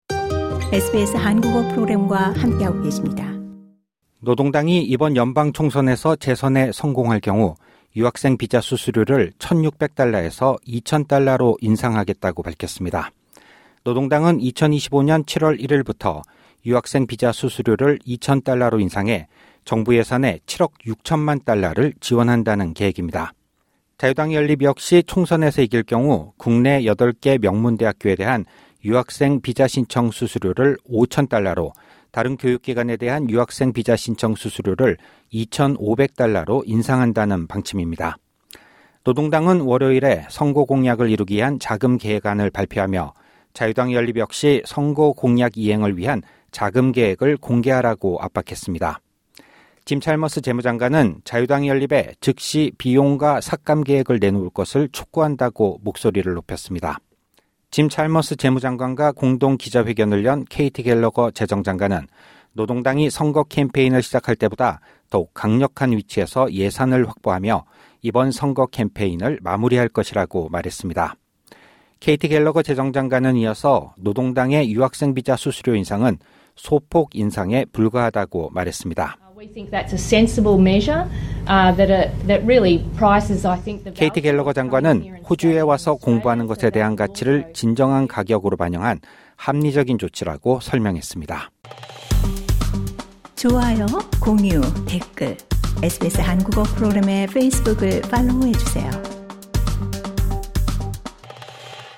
LISTEN TO 이민자 표심 잡기 경쟁…'교육·문화 지원' 공약 맞불 SBS Korean 01:51 Korean 상단의 오디오를 재생하시면 뉴스를 들으실 수 있습니다.